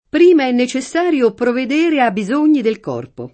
provveder0+]; per il resto, coniug. come vedere — per provvide, err. provvedette — antiq. provedere [proved%re], coniug. sim.: prima è necessario provedere a’ bisogni del corpo [